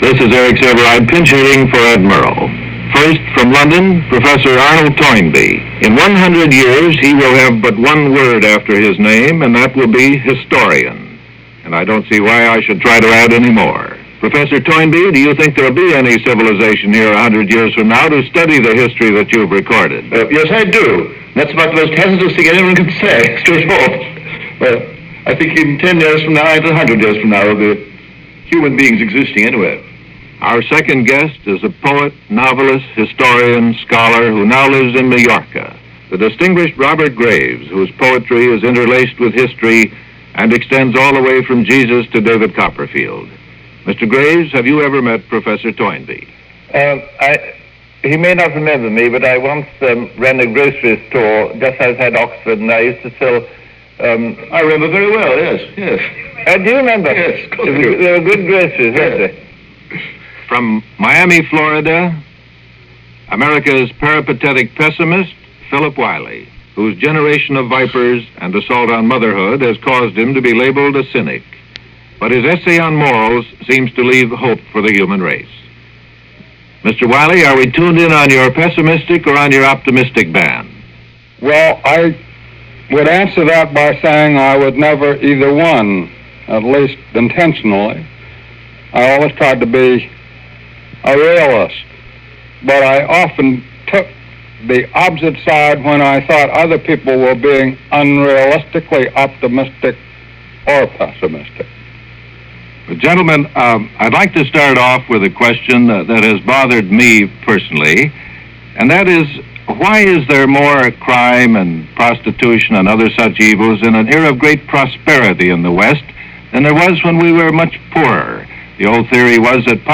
On this program, moderated by Eric Sevareid, the guests include Arnold Toynbee, Robert Graves and Philip Wylie – all figures of considerable prominence during the earlly decades of the 20th century.